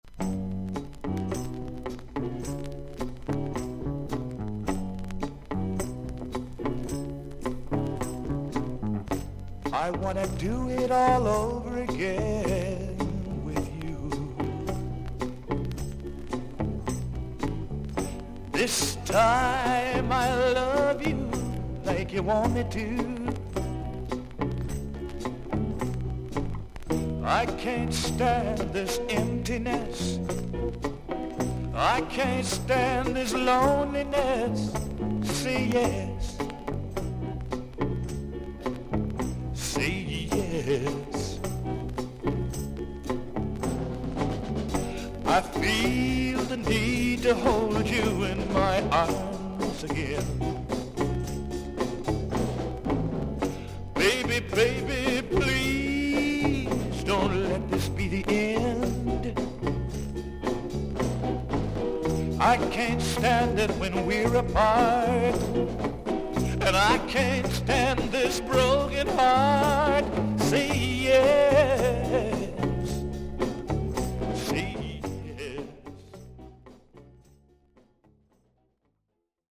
少々軽いパチノイズの箇所あり。少々サーフィス・ノイズあり。クリアな音です。
ブルース/R&B/フォーク・シンガー/ギタリスト。ギター、ベース、ドラムスのコンボで自作曲を中心に歌っています。